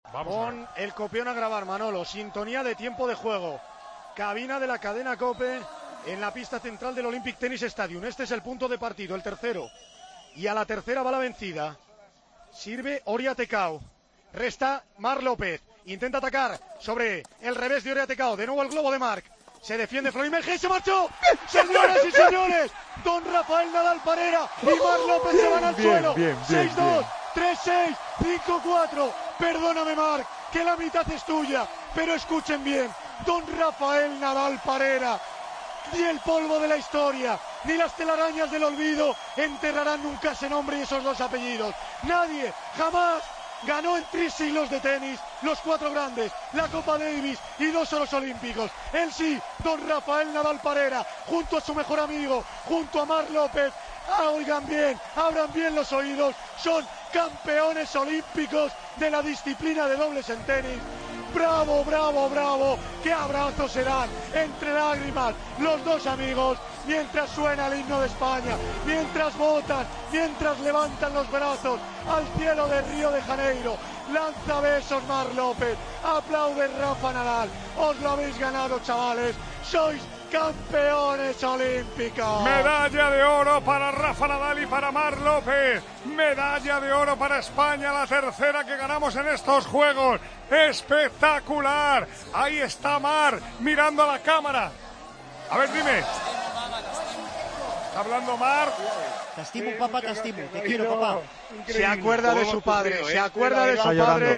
Escucha la narración del punto que le dio el oro a Rafa Nadal y Marc López